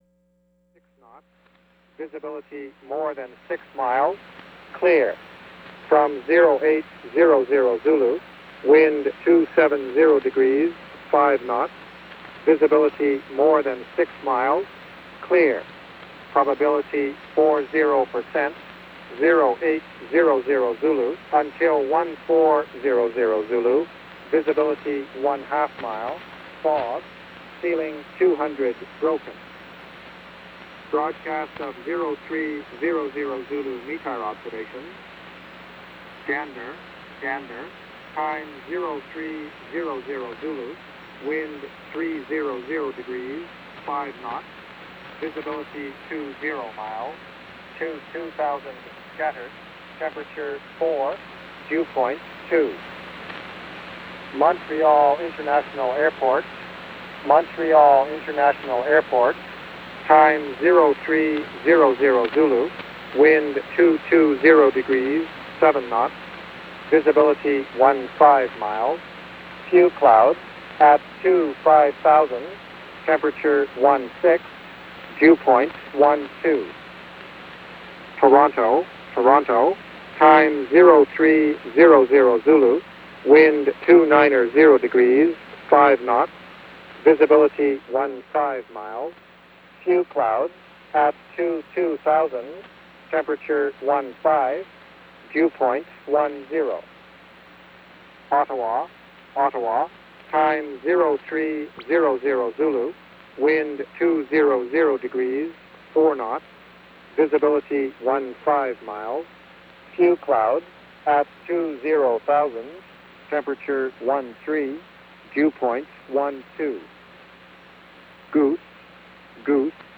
Gander, Canada WX Report. Already snowing in Canada!